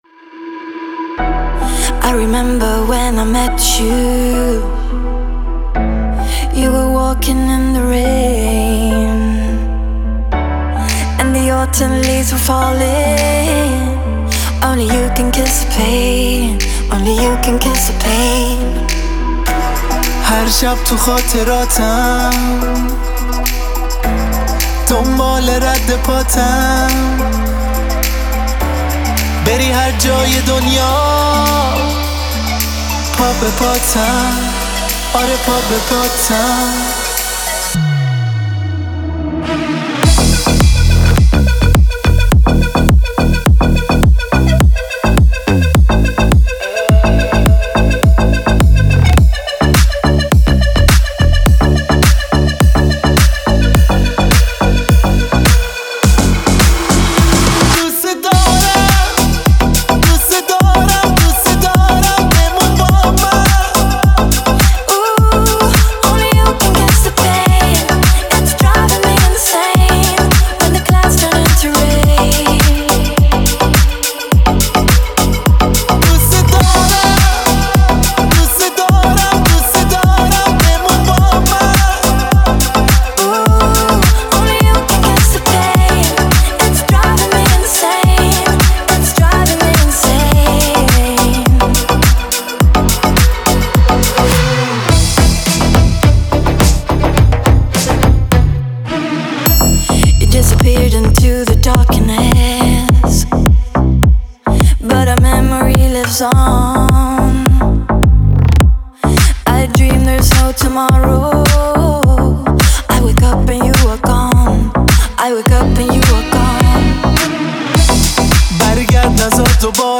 Клубная музыка
клубные ремиксы